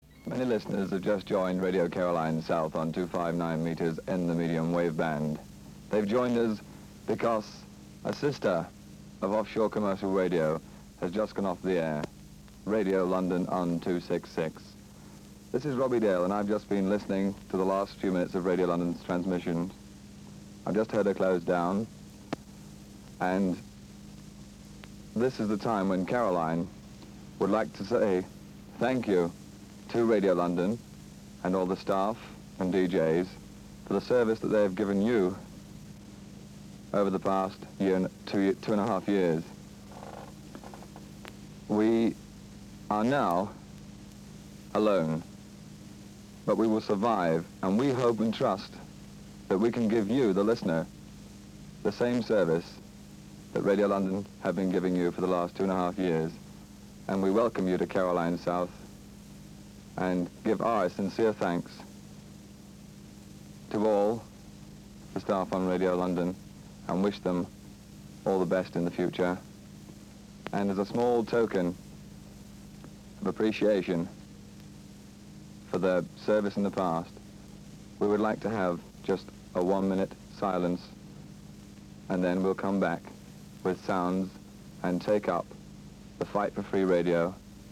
tribute to Radio London on Radio Caroline South, 14th August 1967